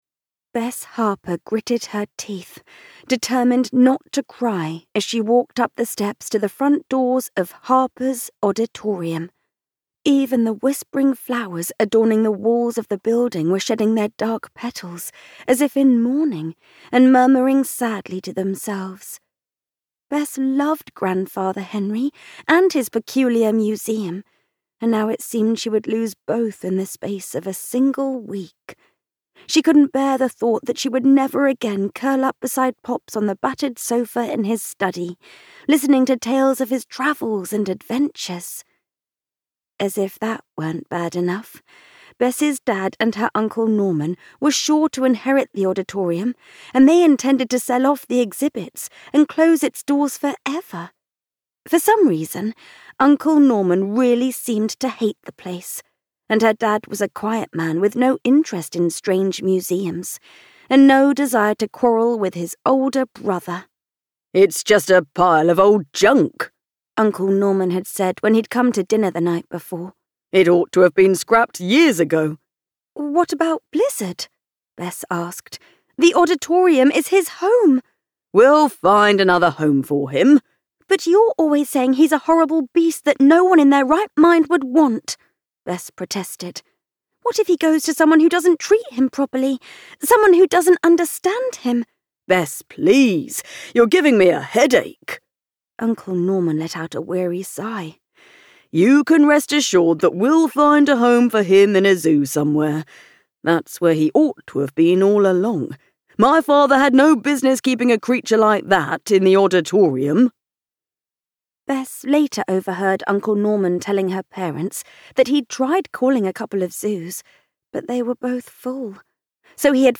The Train of Dark Wonders - A Train of Dark Wonders Adventure, Book One - Vibrance Press Audiobooks - Vibrance Press Audiobooks